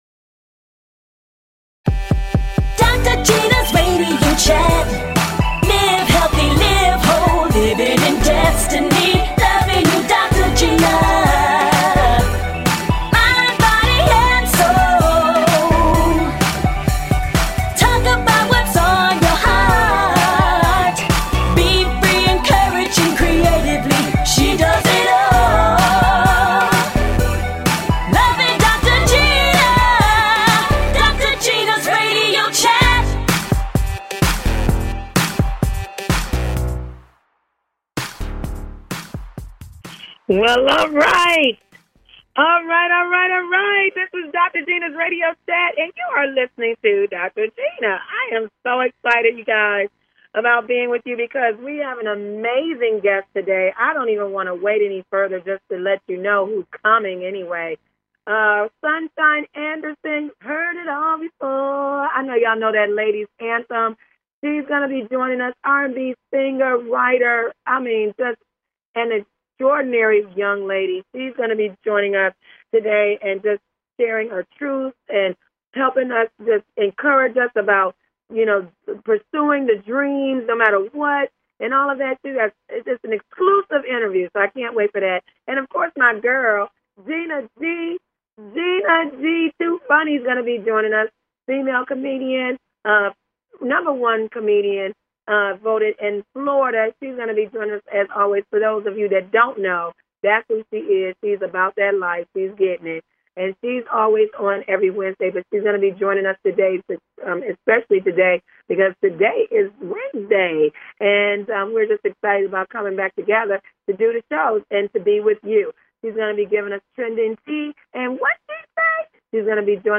Talk Show
Fun! Exciting! And full of laughter!